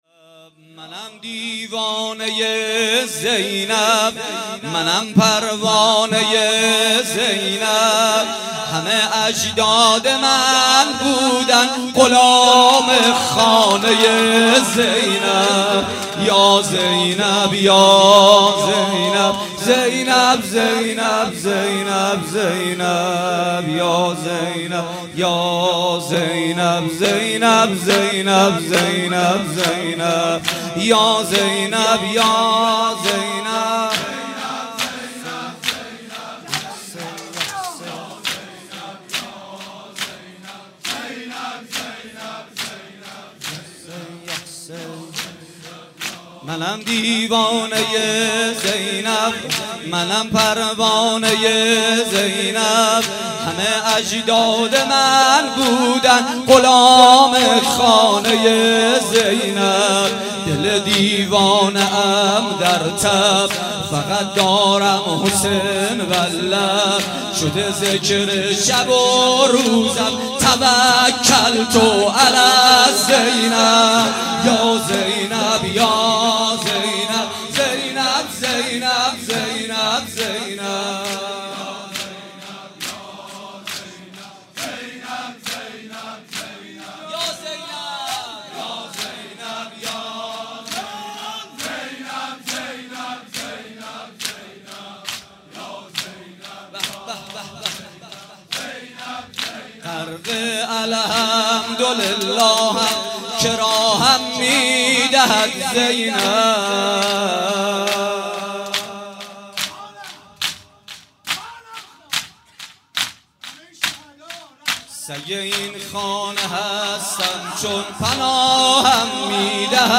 شب شهادت حضرت زینب/هیات ریحانه النبی
با مرثیه سرایی : محمدحسین پویانفر برگزار گردید.